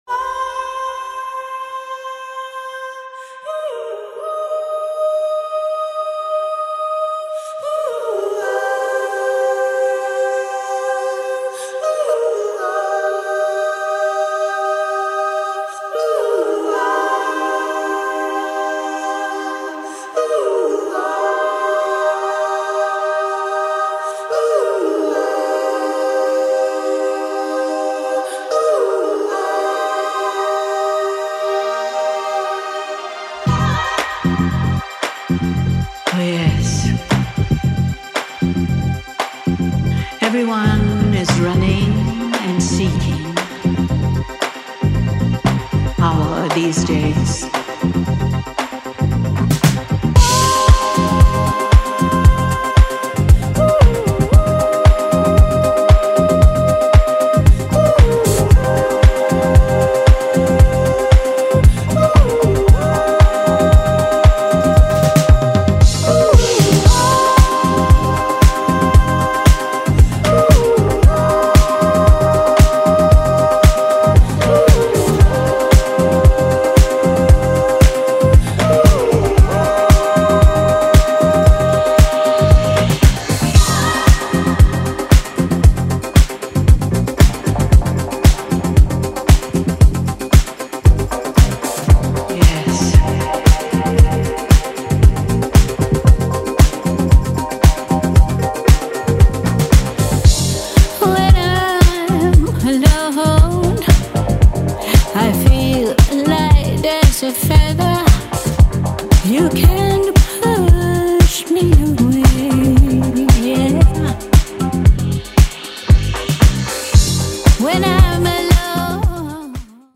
どちらもよりモダンでダビーなエレクトリック・ディスコへと昇華してみせた、全バージョン楽しめる1枚に仕上がっています。